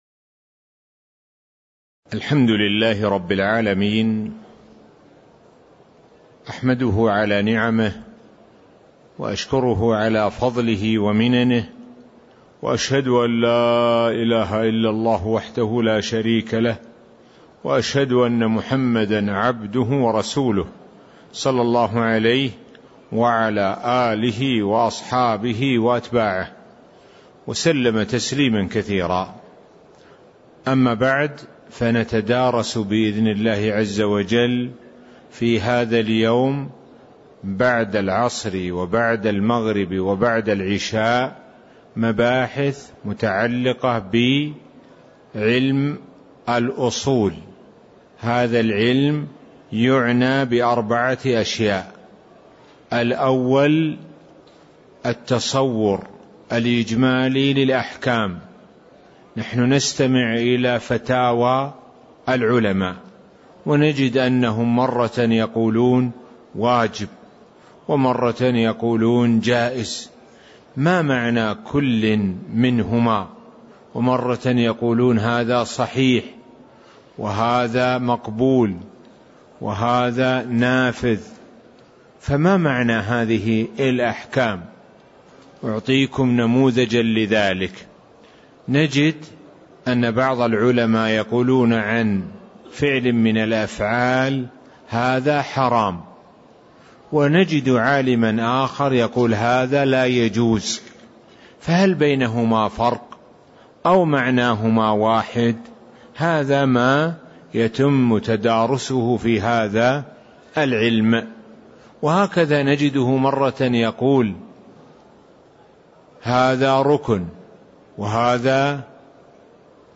تاريخ النشر ٩ شوال ١٤٣٦ هـ المكان: المسجد النبوي الشيخ: معالي الشيخ د. سعد بن ناصر الشثري معالي الشيخ د. سعد بن ناصر الشثري مقدمة الرساله (001)